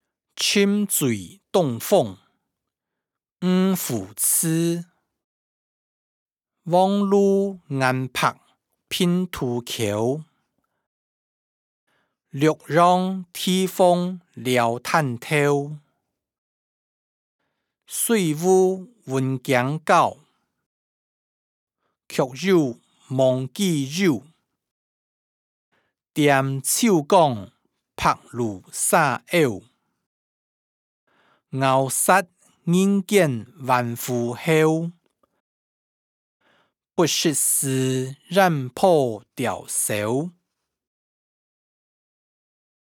詞、曲-沉醉東風：漁父詞音檔(海陸腔)